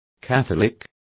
Complete with pronunciation of the translation of catholics.